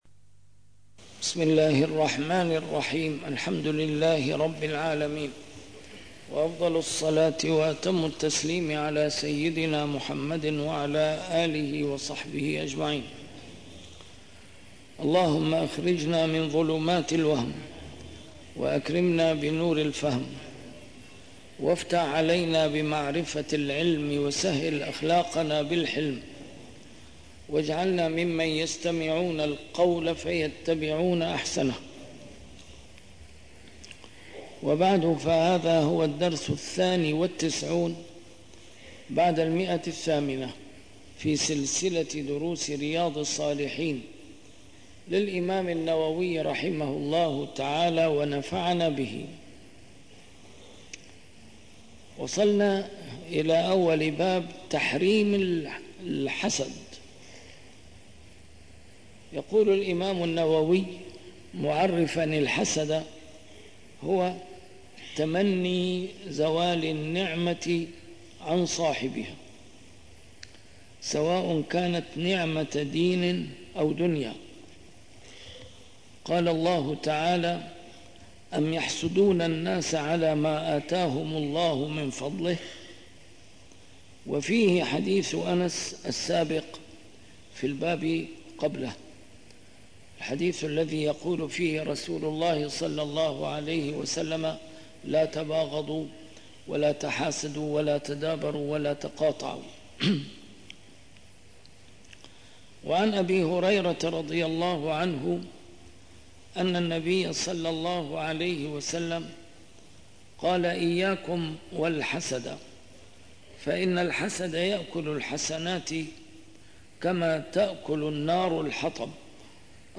A MARTYR SCHOLAR: IMAM MUHAMMAD SAEED RAMADAN AL-BOUTI - الدروس العلمية - شرح كتاب رياض الصالحين - 892- شرح كتاب رياض الصالحين: تحريم الحسد